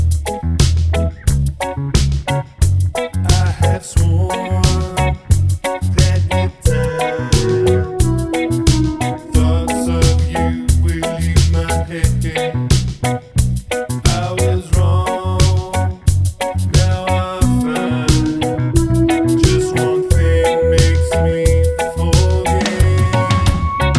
Two Semitones Down Reggae 3:08 Buy £1.50